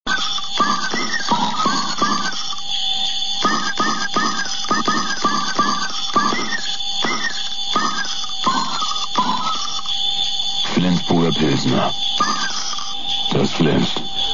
Flensburger Weihnachtswerbespot 2005
flens_weihnachtswerbung_posradio.mp3